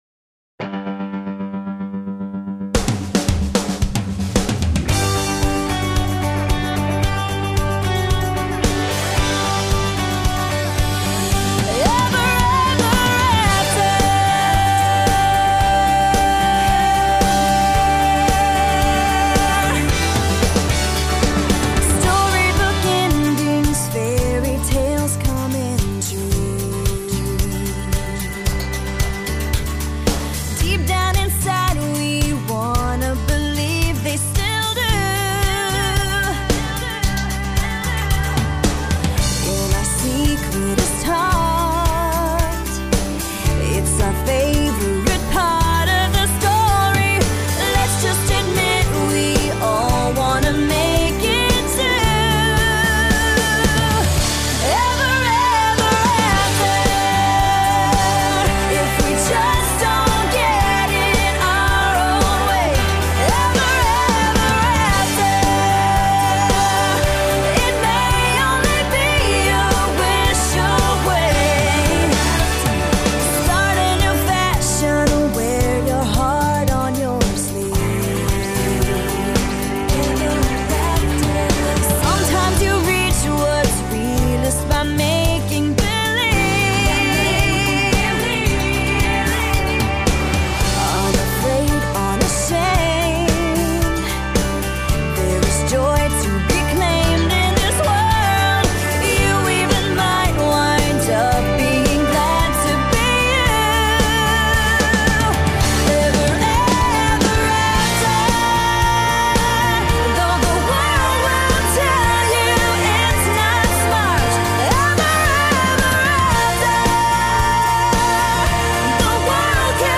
Team Bond Geschaeftspresentationsanruf 23. Mai, 2011